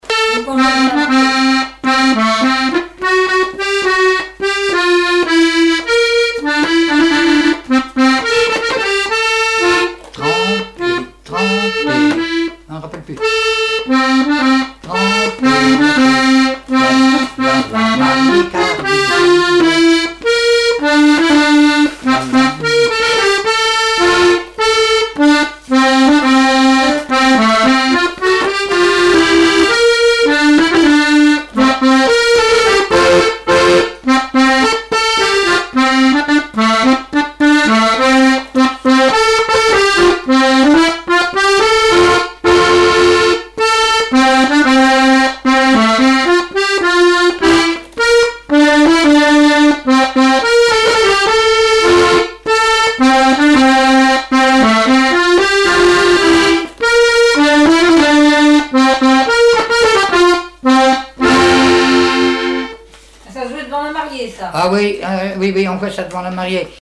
avant-quatre pour mener le cortège de noce
danse : quadrille : avant-quatre
Répertoire du musicien sur accordéon chromatique
Pièce musicale inédite